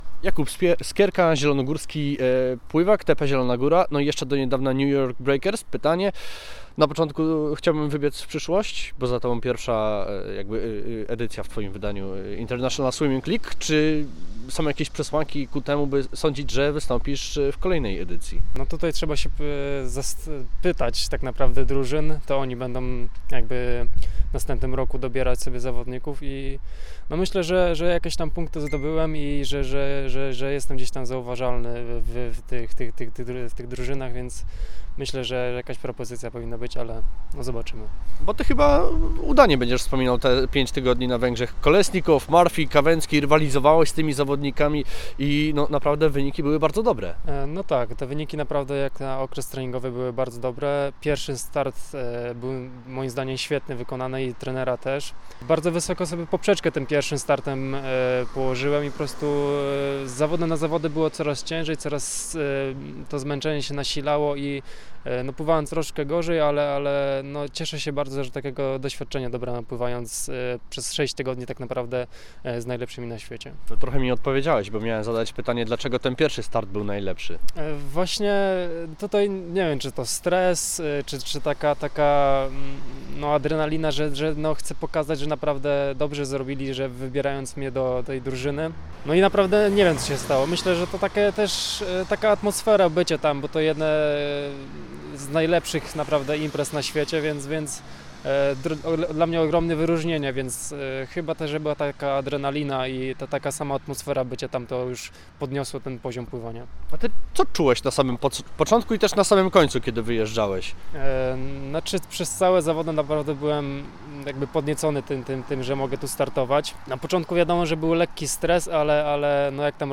My zapytaliśmy zielonogórzanina jak wspomina pobyt na Węgrzech, oraz na co liczy w zbliżającym się grudniowym okresie.